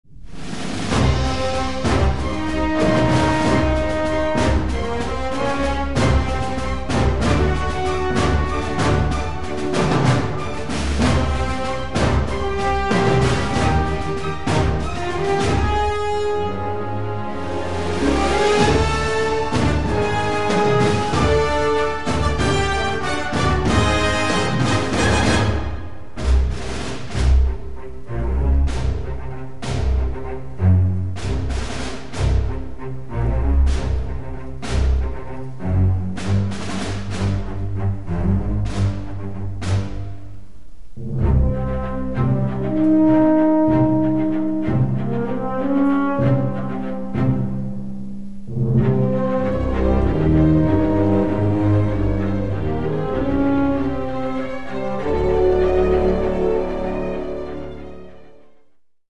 Music clip 1, 0'59" (693Kb) [DVD menu music: mission music]